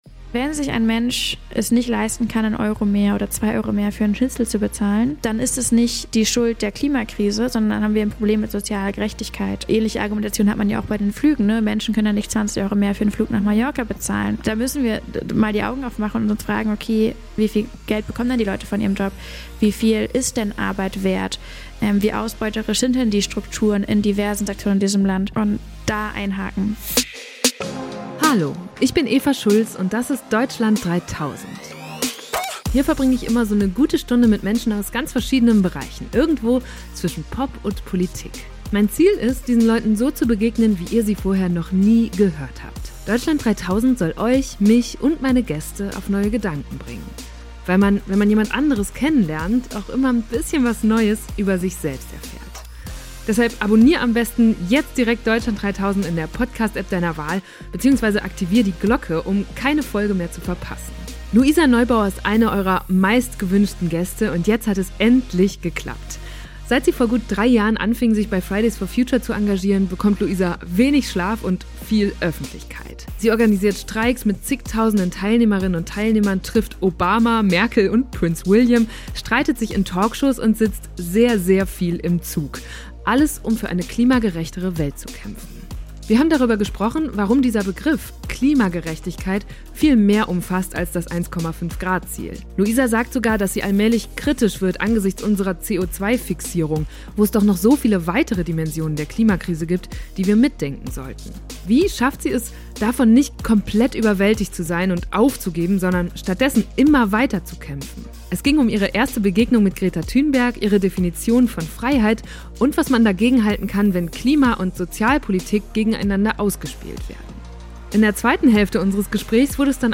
Wir haben diese Folge wieder an einem besonderen Ort aufgezeichnet: in der geomorphologisch-geologischen Sammlung der Humboldt-Universität in Berlin.